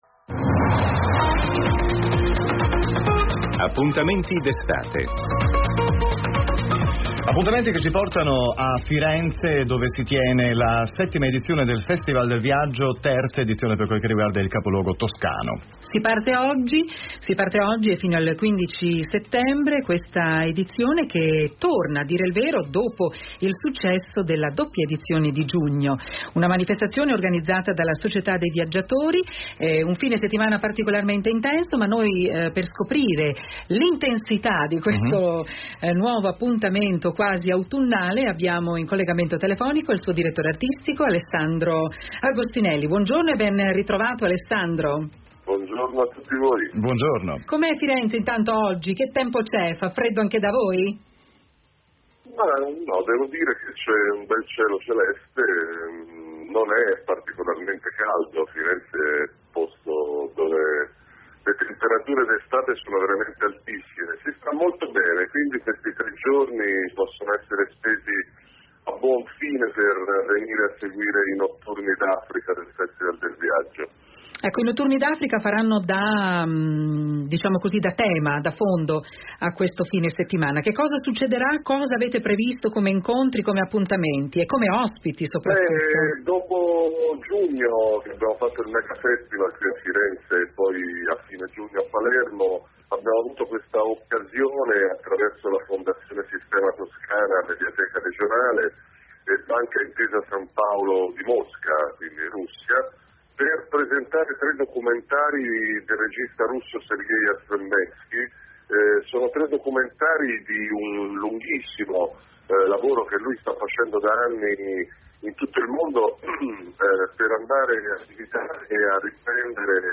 Radio Capodistria – Intervista